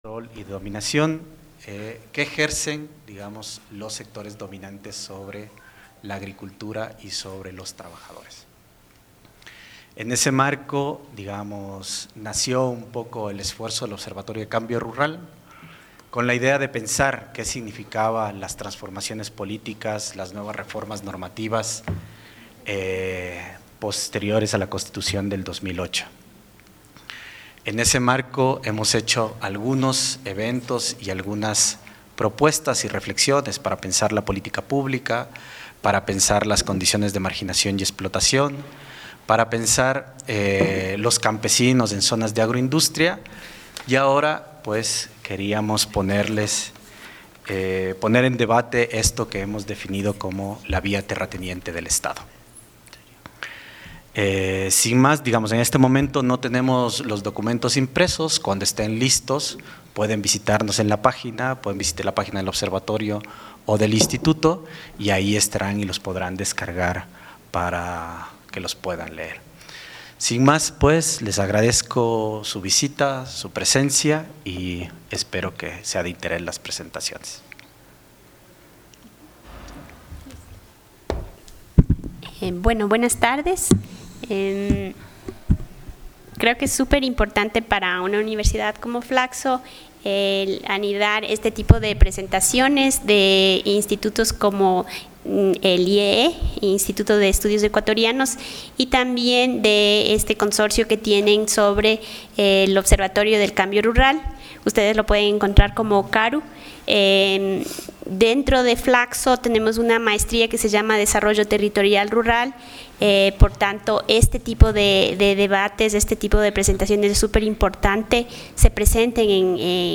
Conversatorio